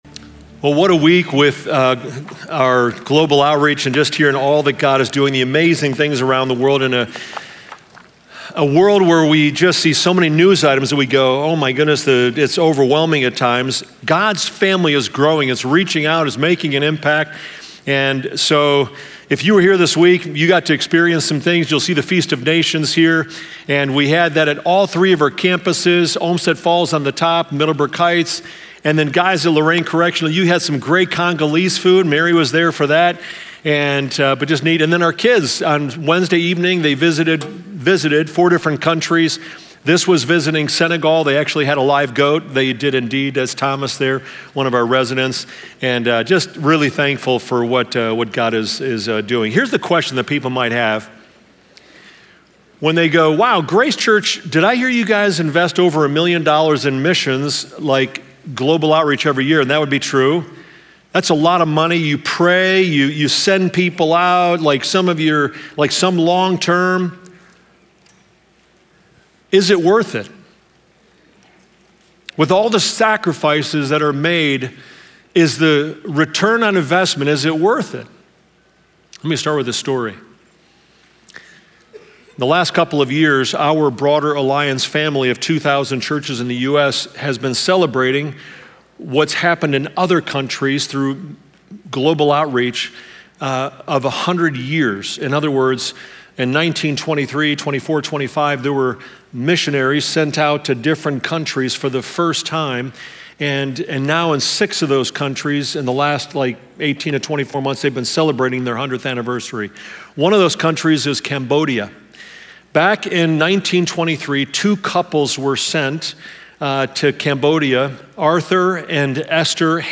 Join us for this conversation on faith, family, and living with open hands.